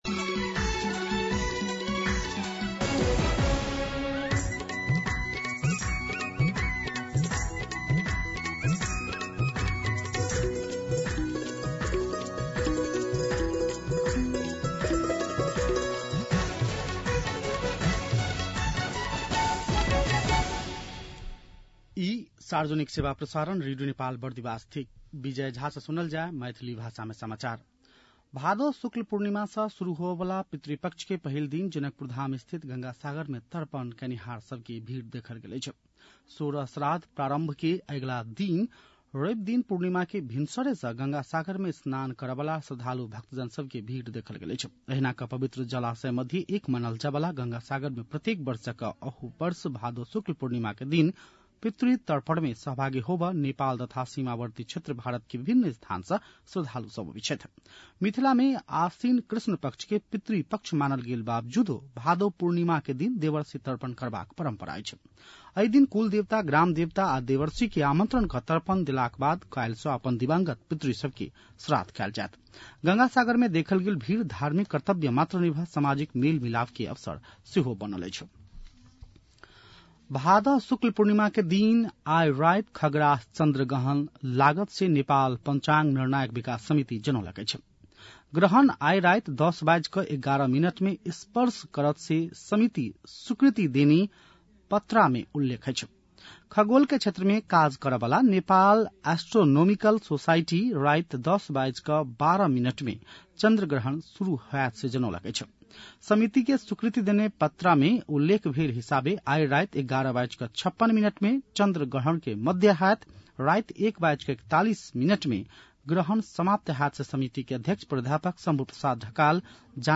मैथिली भाषामा समाचार : २२ भदौ , २०८२